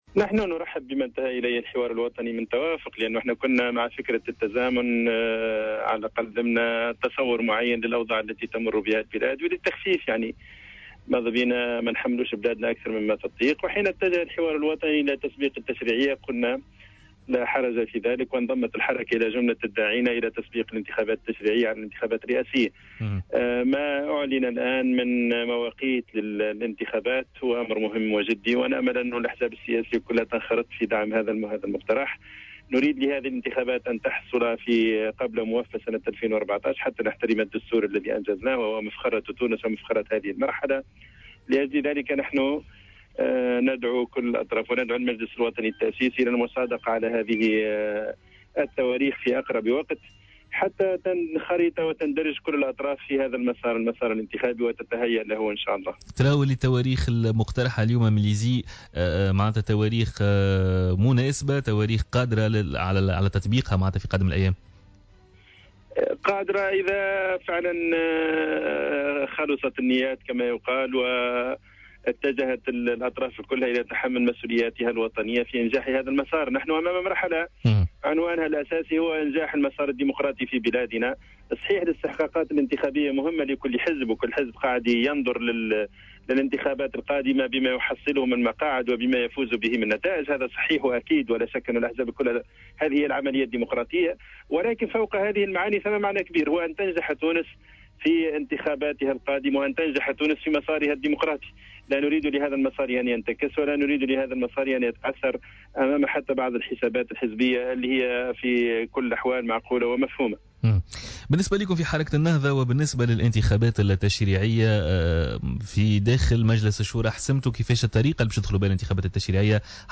Le président du conseil de la Choura du mouvement Ennahdha, Fethi Ayadi, a indiqué dans une déclaration accordée à Jawhara FM ce mercredi 18 juin 2014, qu’il était possible d’établir une alliance avec le CPR, Ettakatol et le parti Républicain, après les élections.